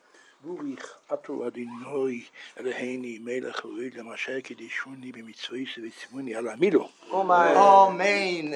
מעמד הברית התקיים בבית סמוך לביהמ"ד הגדול ומרן רבינו שליט"א נתכבד בסנדקאות.
הקלטה: רבינו שליט"א באמירת הברכות